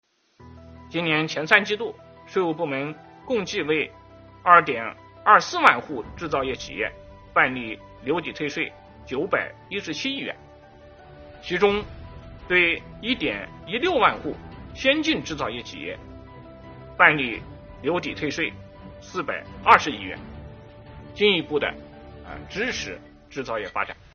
11月5日，国务院新闻办公室举行国务院政策例行吹风会，国家税务总局副局长王道树介绍制造业中小微企业缓税政策等有关情况，并答记者问。